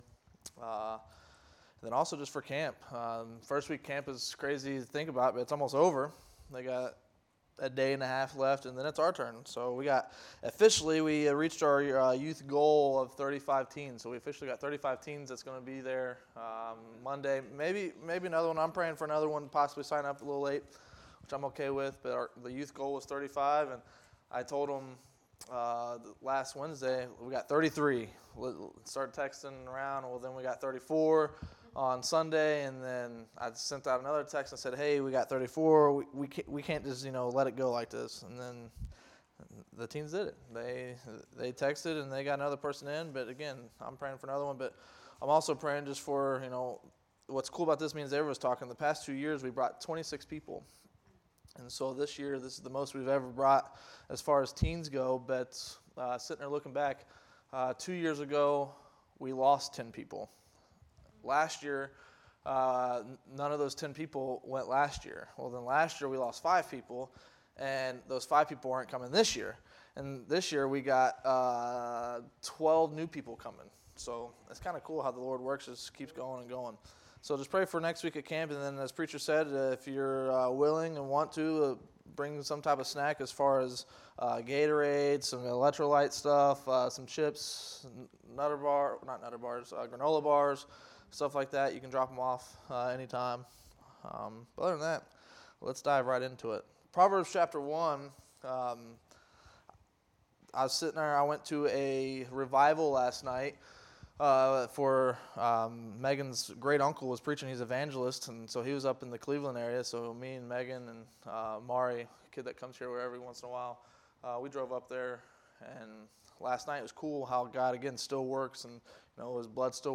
Wednesday Noon Bible Study